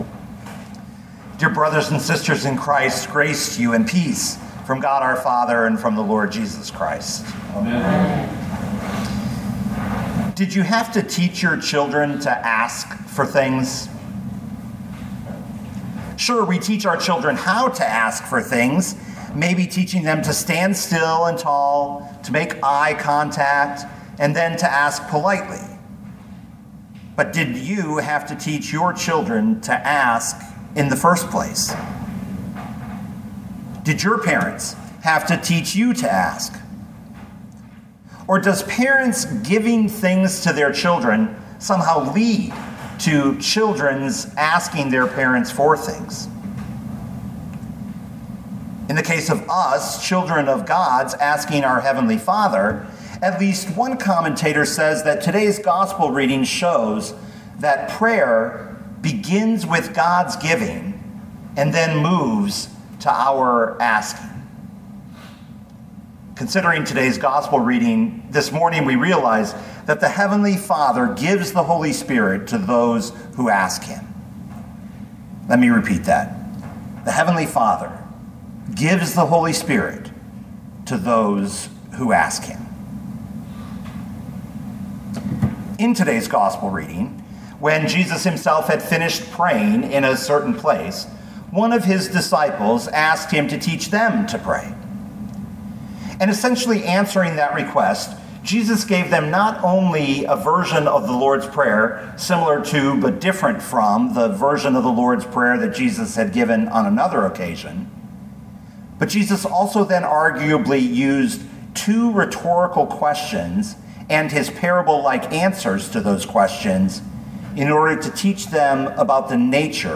2022 Luke 11:1-13 Listen to the sermon with the player below, or, download the audio.